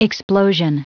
Prononciation du mot explosion en anglais (fichier audio)
Prononciation du mot : explosion